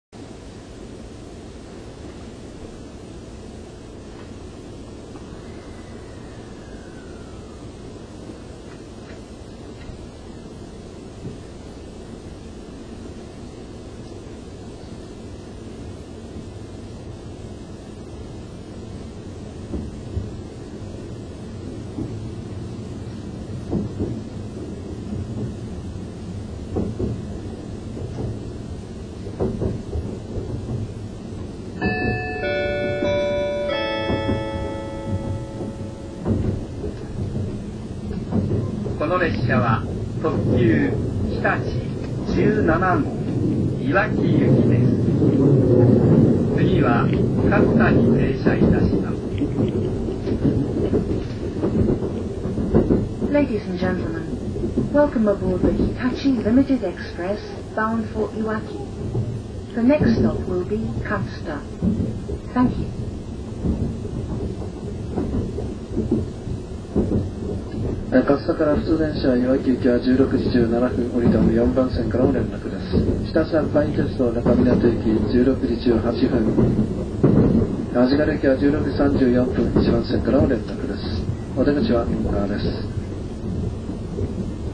車内放送・構内放送
収録機器/レコーダー：オリンパス ボイストレック V51　　　マイク：オーディオテクニカ　AT9842
※各ファイルはビットレートを圧縮しています。このため音質が低下しています。